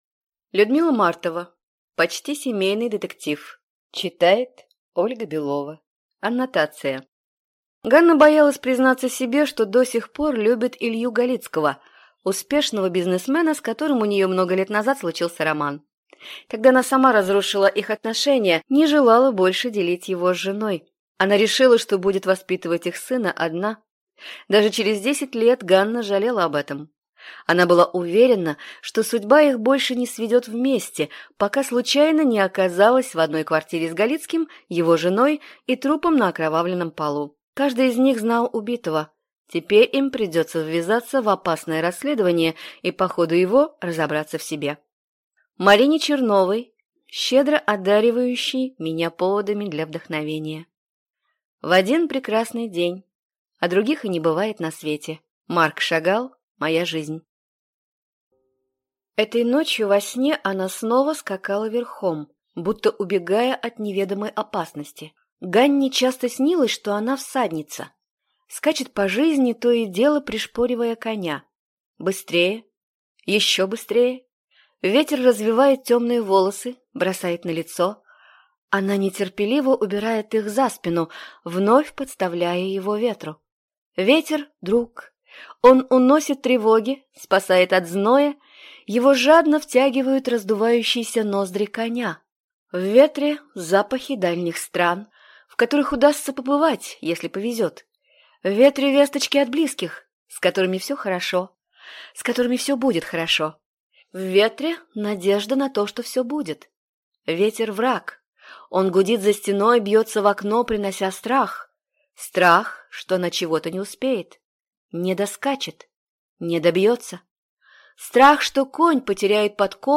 Аудиокнига Почти семейный детектив | Библиотека аудиокниг